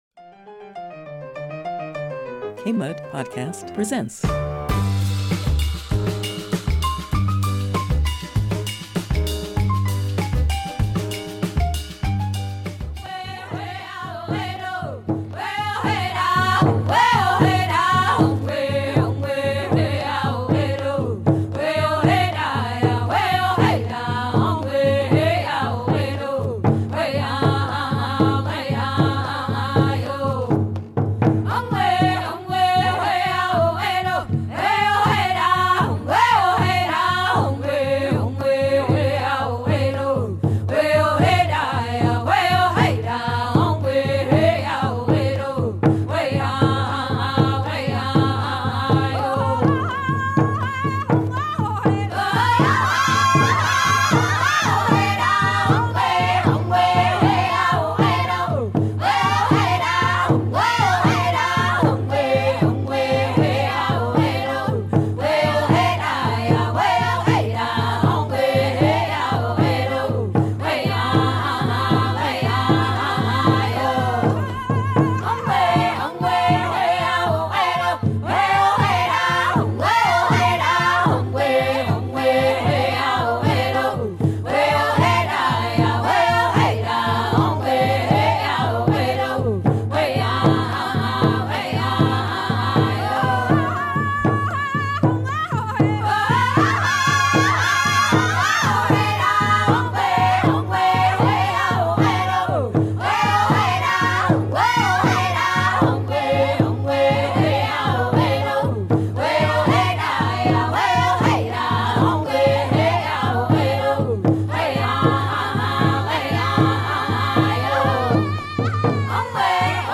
Native women speak the truth. Stories and Poems from Native Women from many nations from Turtle Island.